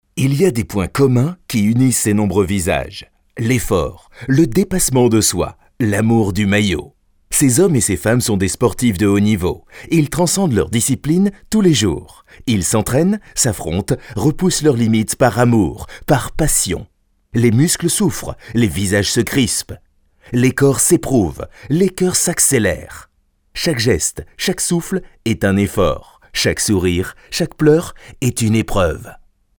Sprechprobe: Sonstiges (Muttersprache):
French native (no accent) middle age male voice-talent since 1988, i have my own recording facilities and deliver in 2 to 6 hours ready to use wavs/mp3 files, paypal accepted, my voice is clear sounding serious but friendly at the same time !